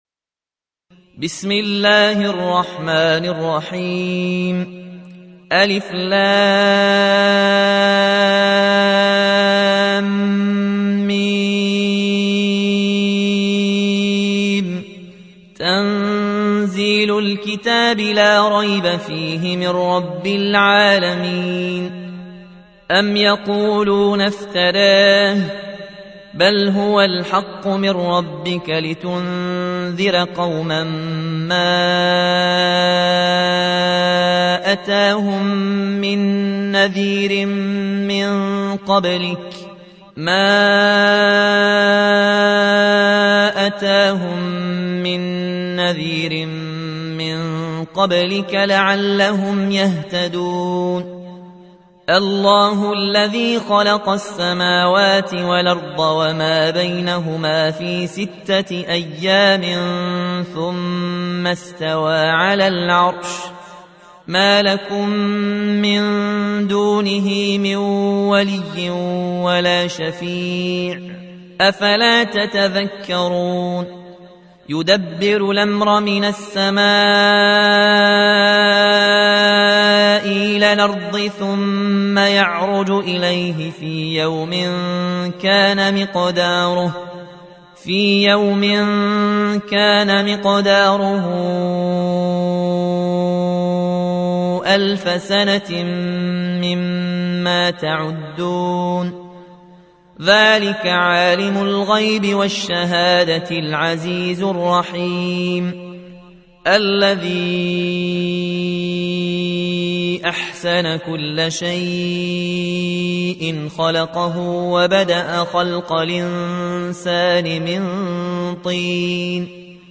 32. سورة السجدة / القارئ